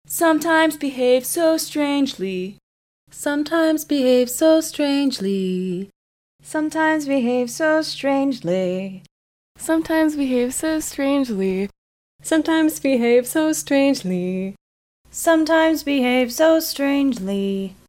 To make sure that these subjects were able repeat the pitches after a single hearing, we then had them listen only once to the phrase as sung rather than spoken, and again asked them to repeat back exactly what they had heard. Here are the reproductions of the same six subjects that you just heard, and you can see that they had no problem reproducing the sung melody.